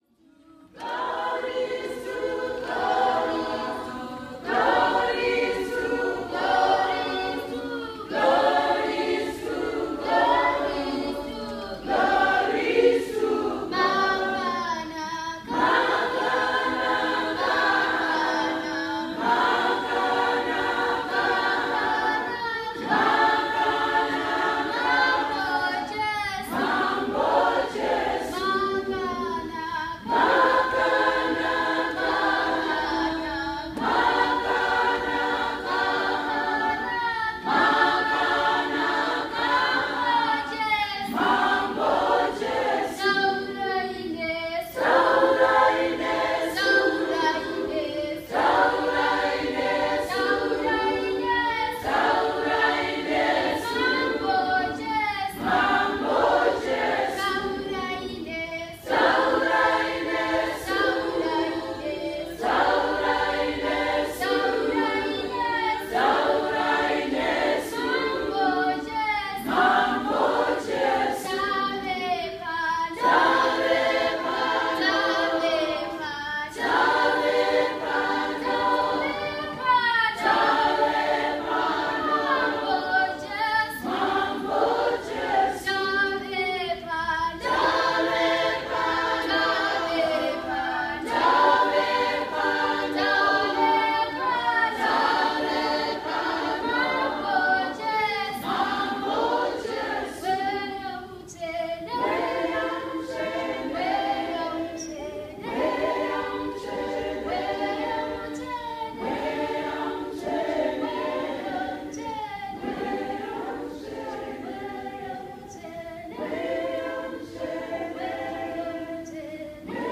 I saw them celebrate each new day in song, hunker down for sometimes-intense deliberations, and then rise back into the clouds – pulling me with them – in a chorus as powerful as any cathedral could house.
Their songs traveled from somber to soaring, taking good time between points on an ethereal journey.  Their hymns at once carried cries, only to cascade into pools of joy swirled by tempests raging against monsters that might rise before them.